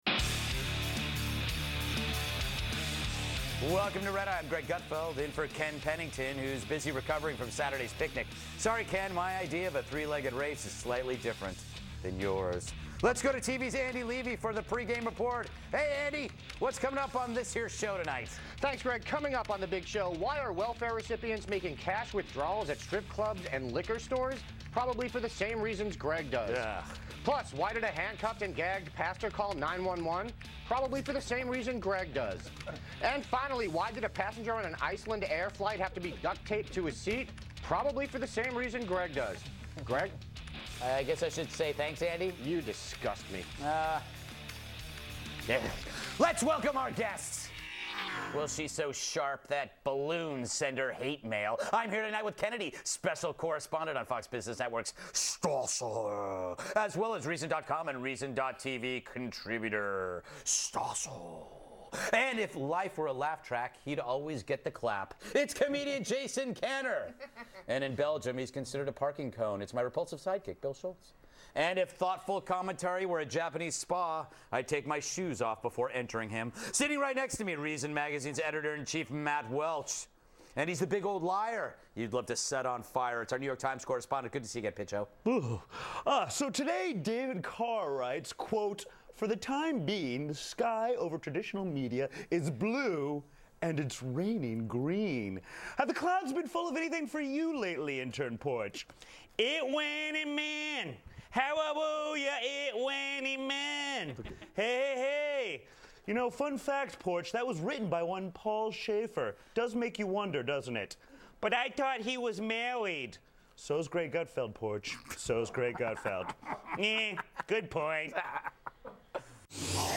Reason's Matt Welch and Kennedy joined Greg Gutfeld on Red Eye to discuss welfare recipients taking out cash at strip clubs, Justin Bieber smoking marijuana and other topics of the day.